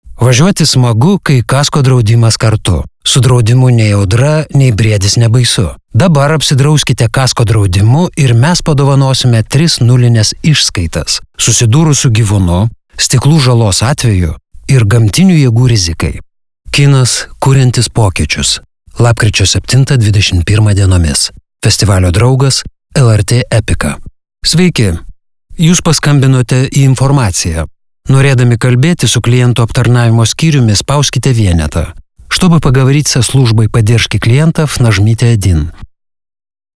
Diktoriai